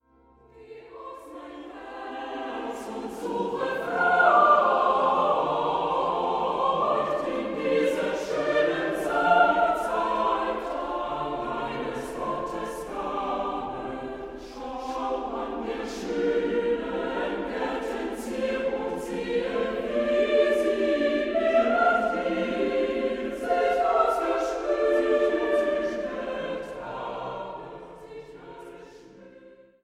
Die geistliche Sommermusik